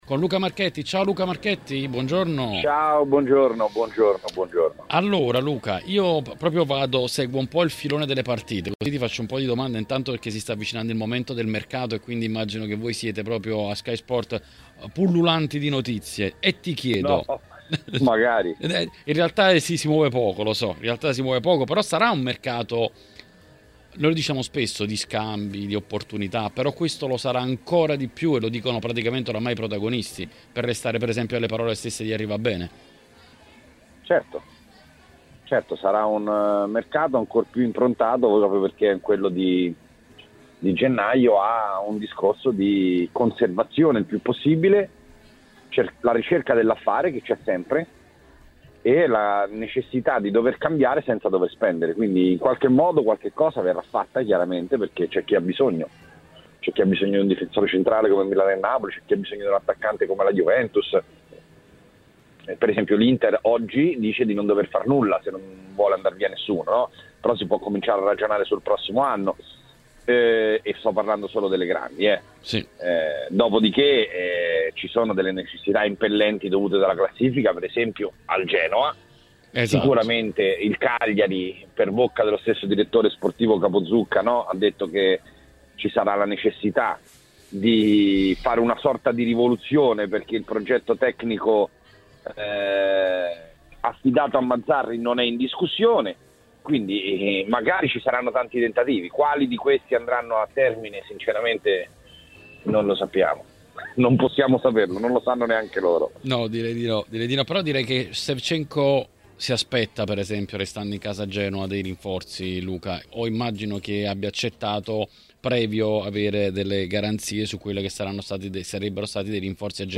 Ospite dell’editoriale del martedì su TMW Radio